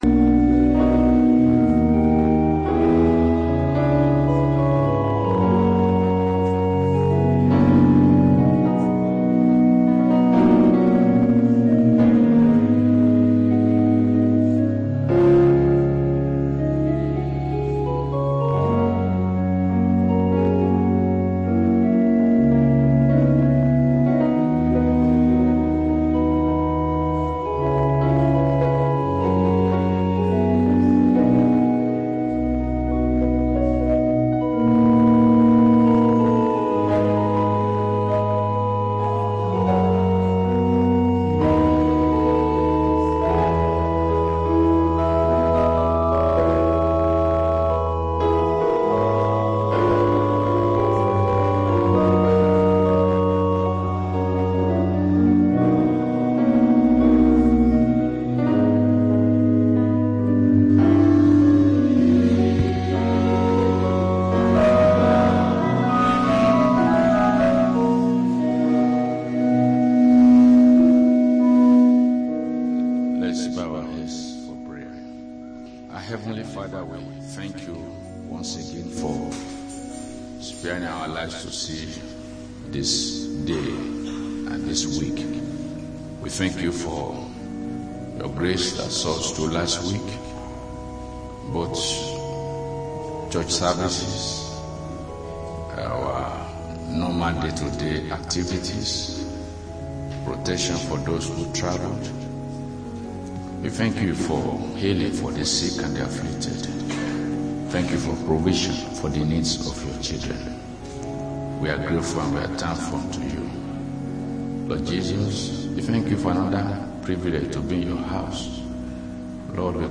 Sunday Main Service